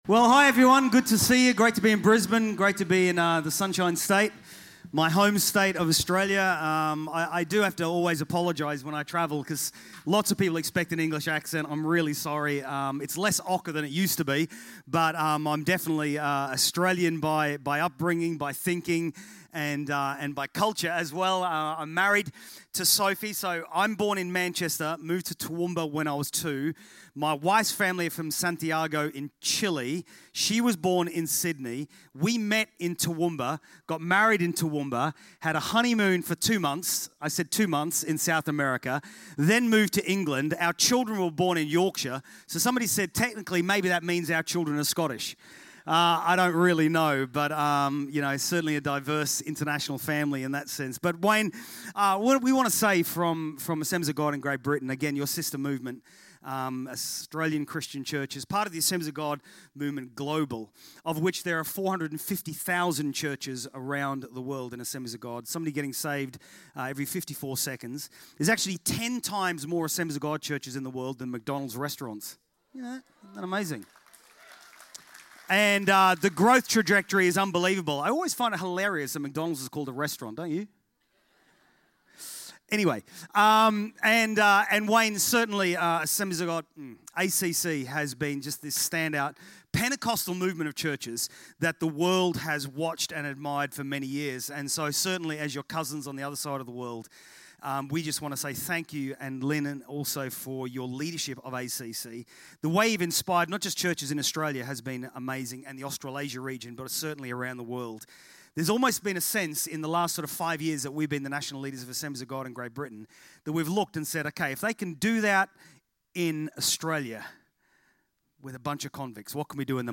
Here you will find weekly podcasts from our Hope Centre services. We hope that these teachings from the Word will draw you closer to the Father heart of God.